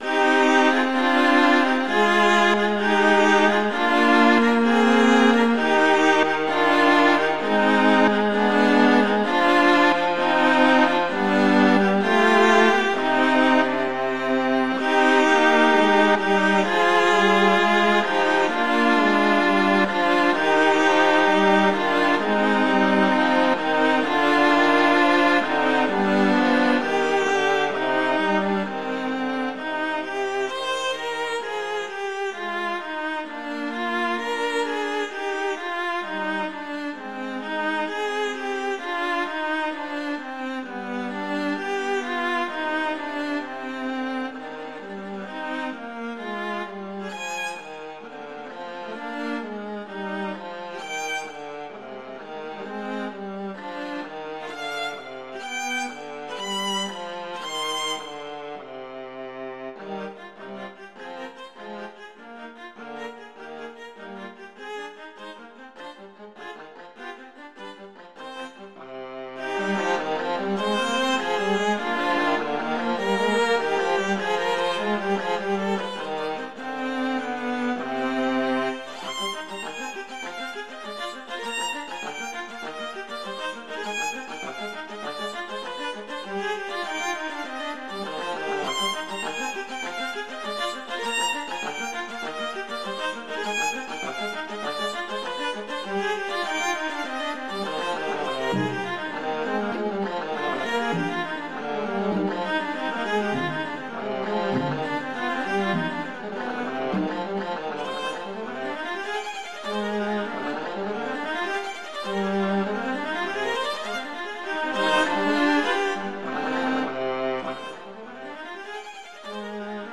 Instrumentation: two violas
classical
G minor
♩=65 BPM (real metronome 66 BPM)